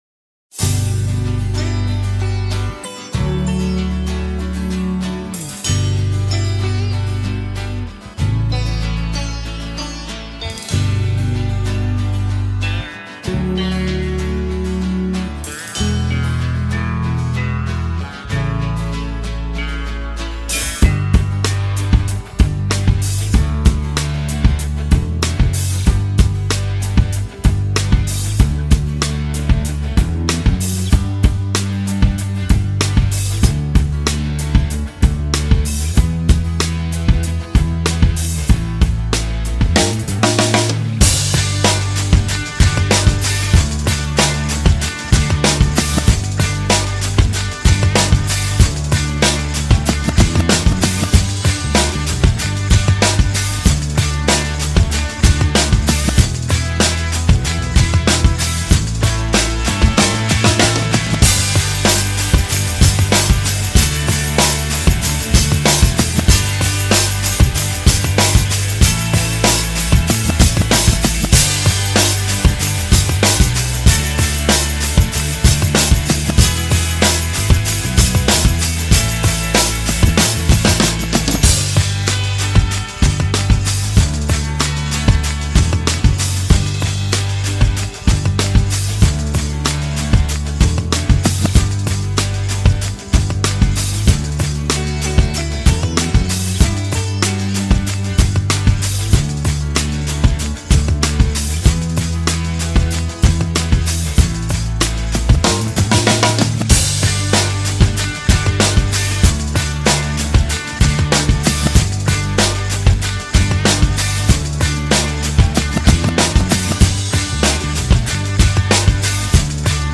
official instrumental
R&B Throwback Instrumentals